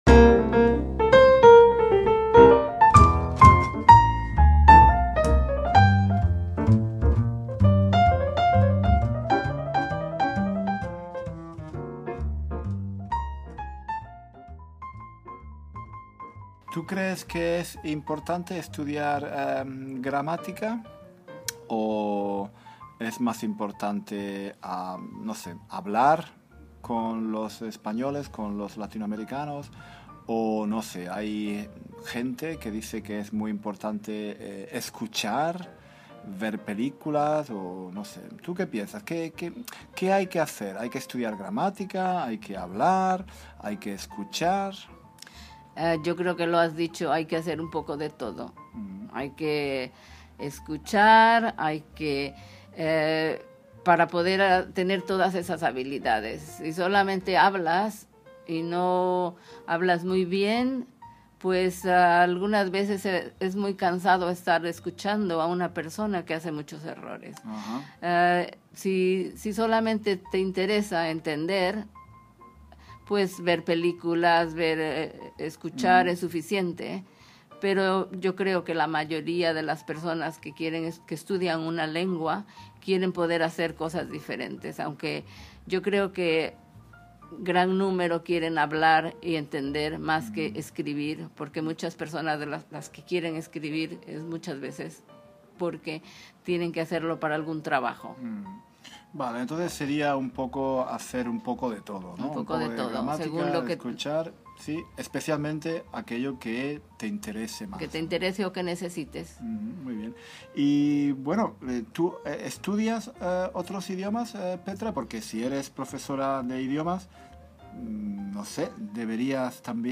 En la segunda parte de nuestra entrevista